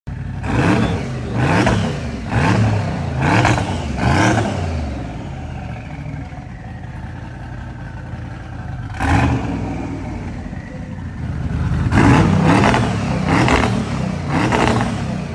Sound On 🔉. 1500hp Twin Turbo sound effects free download
1500hp Twin-Turbo LS Mercedes 190E Evo 2. This thing sounds insane!